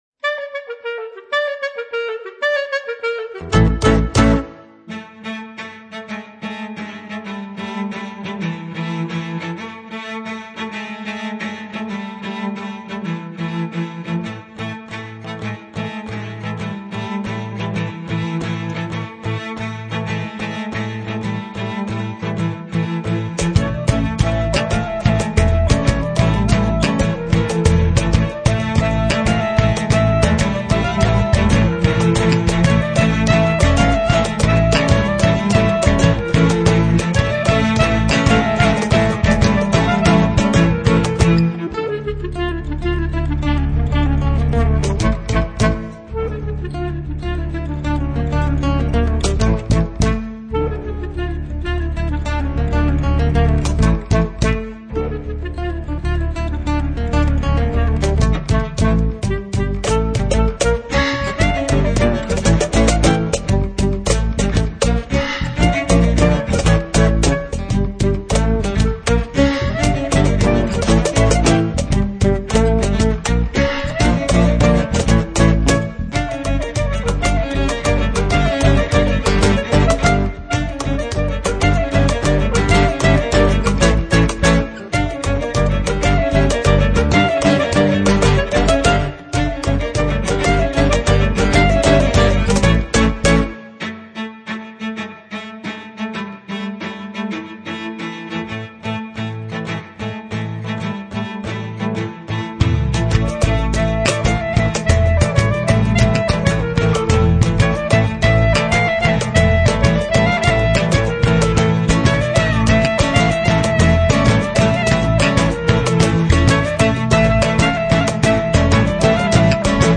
bulgarischer Tanz